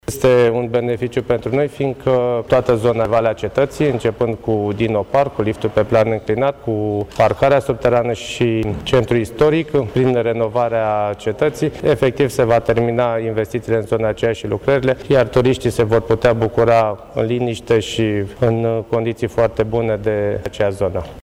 Primarul oraşului Râşnov, Liviu Butnariu: